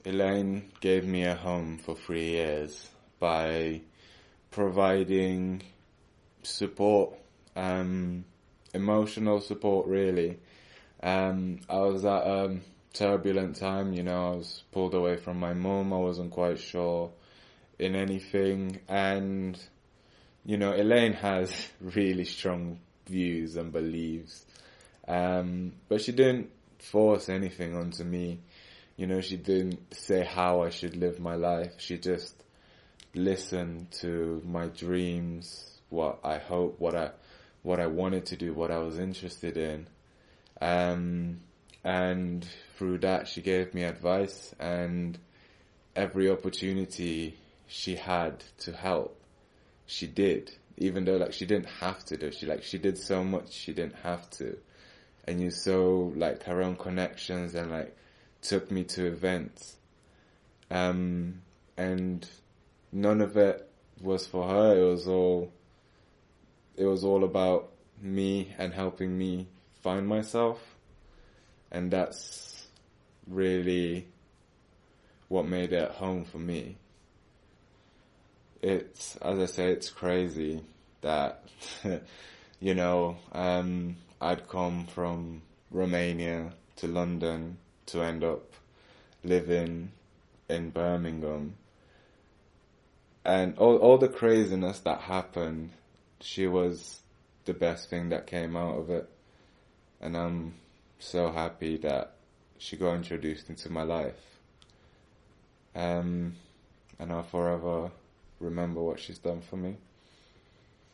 We worked with photographer Vanley Burke and older African-Caribbean women to create Home.